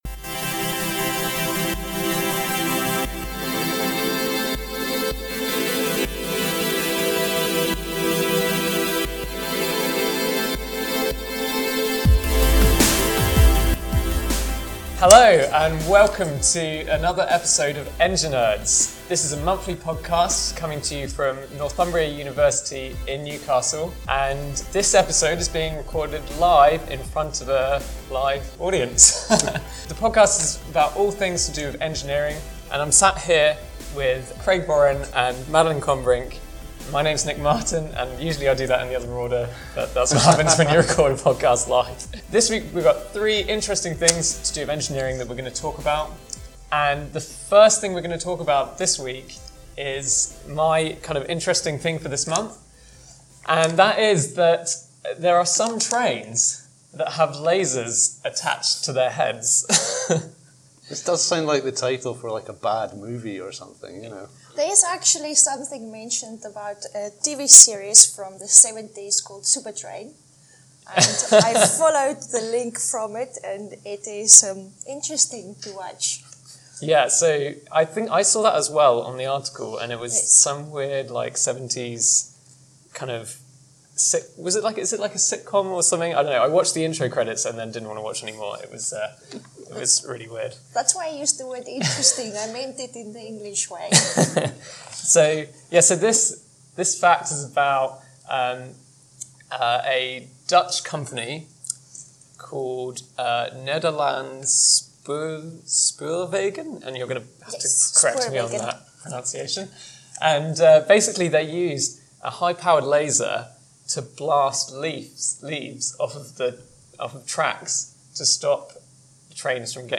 In our first ever recording in front of a live audience, we talked about trains with lasers, memory shape alloy wings and landing on the far side of moon!